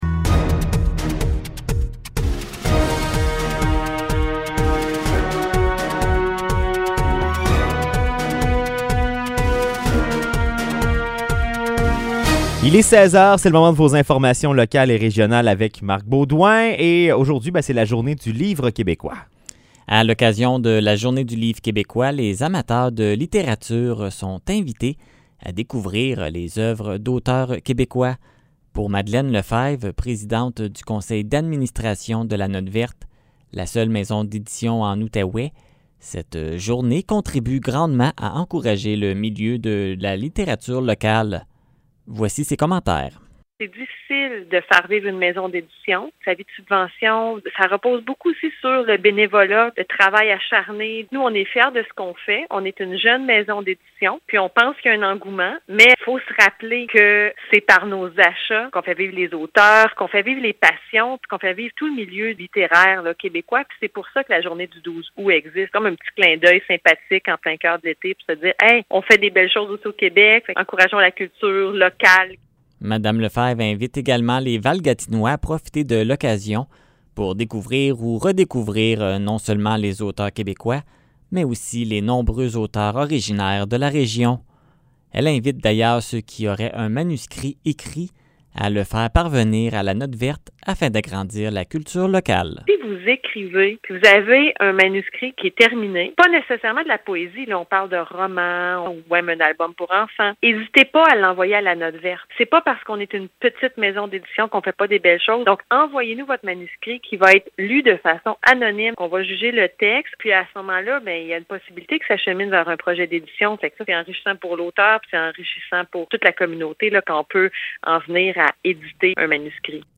Nouvelles locales - 12 août 2021 - 16 h